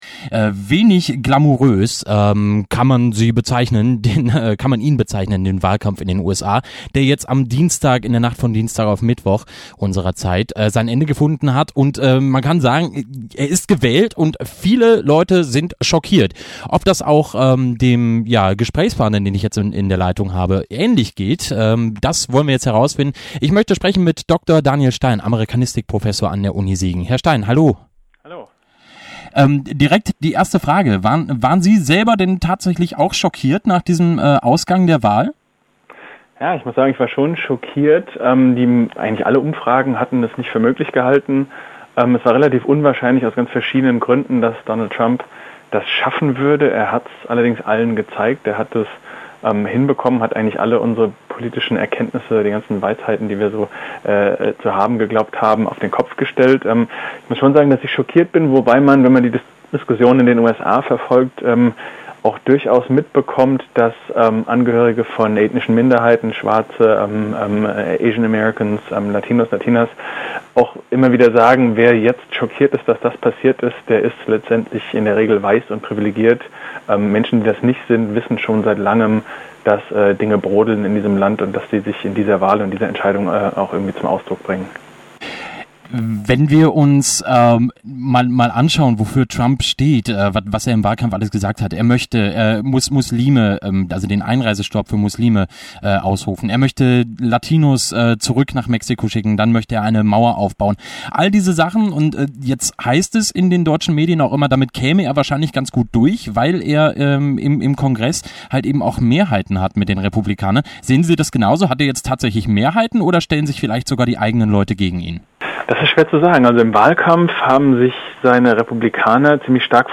interviewed about the US Presidential Elections